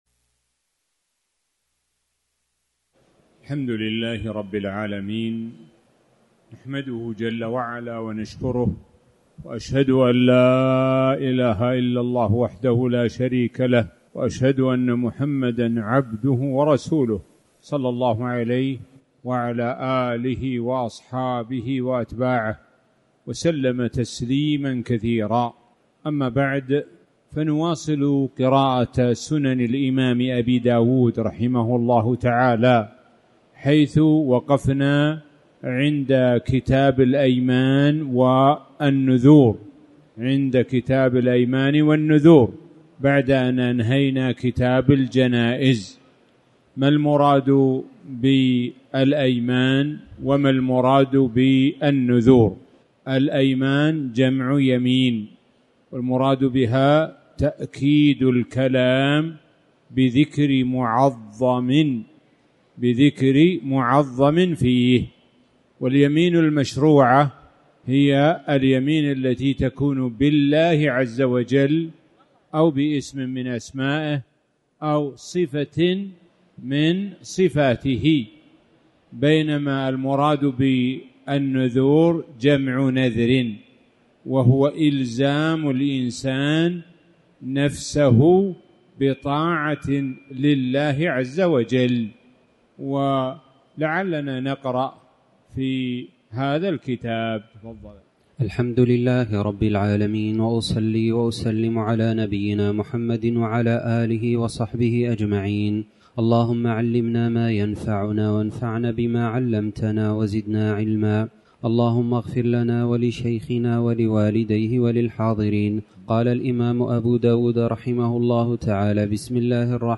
تاريخ النشر ٢٣ ذو القعدة ١٤٣٩ هـ المكان: المسجد الحرام الشيخ: معالي الشيخ د. سعد بن ناصر الشثري معالي الشيخ د. سعد بن ناصر الشثري كتاب الايمان